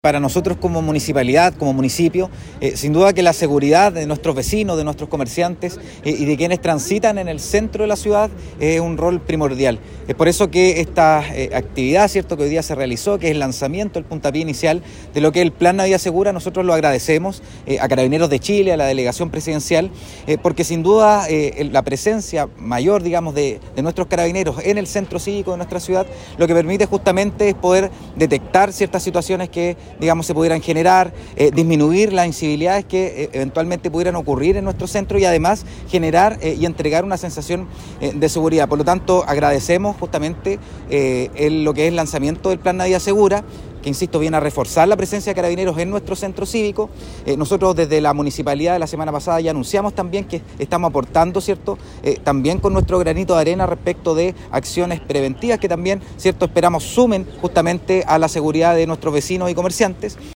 En una ceremonia efectuada en la Plaza Independencia de Concepción, autoridades regionales hicieron entrega formal de 33 nuevos vehículos policiales que serán distribuidos en distintas comunas de la Región, y destacaron que estos recursos logísticos reforzarán la base de la labor policial, los patrullajes, y la operatividad general de Carabineros en el territorio.
El alcalde suplente de Concepción, Boris Negrete, valoró que se priorice la seguridad de vecinos y vecinas, además de los propios comerciantes y quienes transitan por la ciudad.